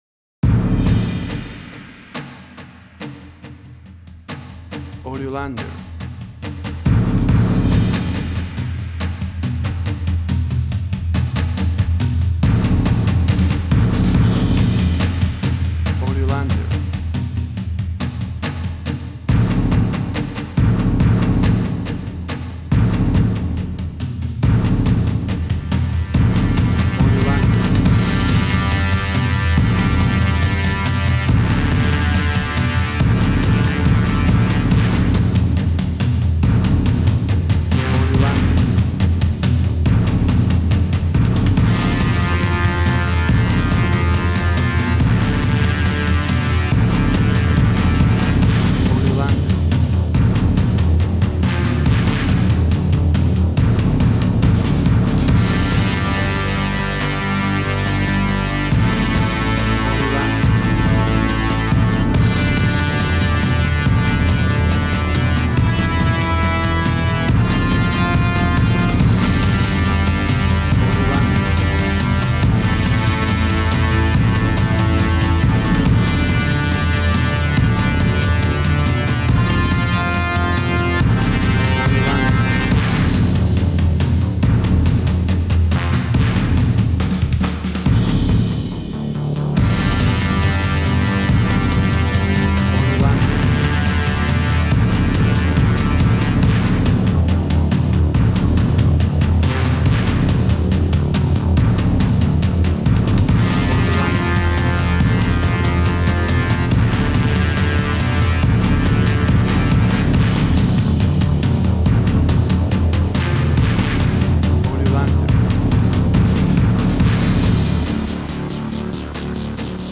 Tempo (BPM): 139